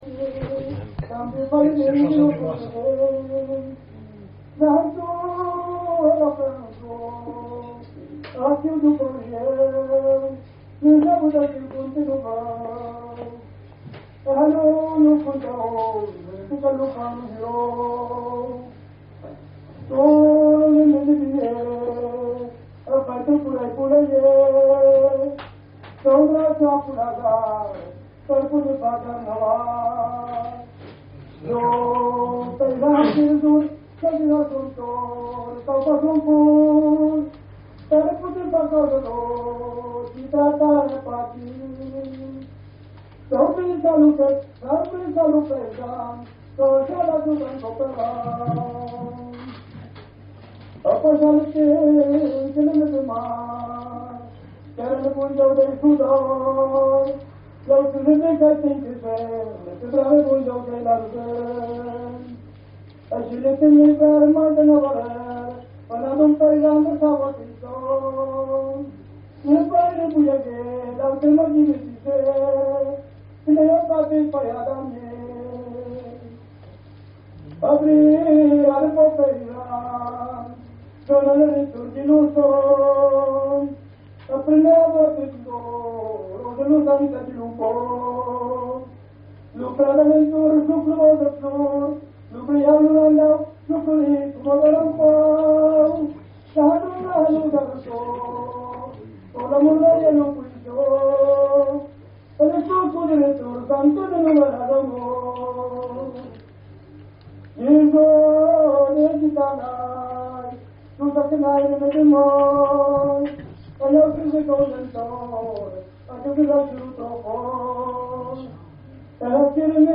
au " Café de la Paix" à TULA le 14  dec 2016